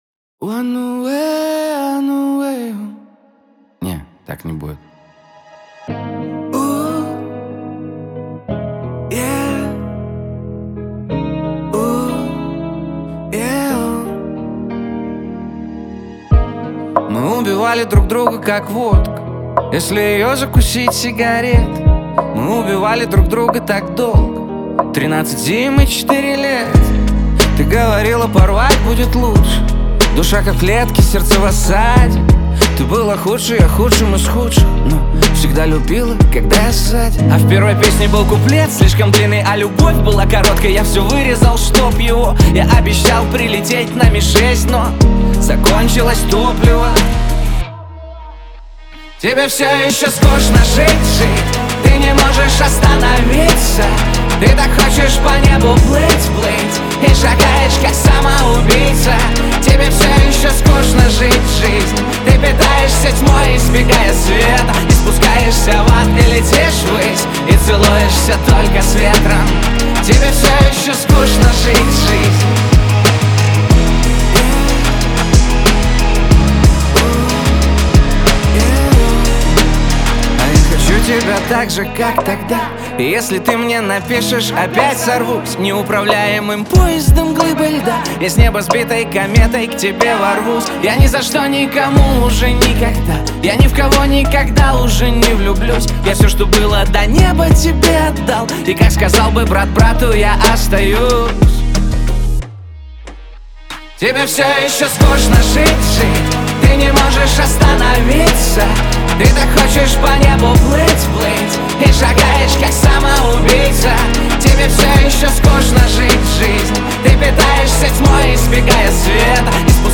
грусть , эстрада , Лирика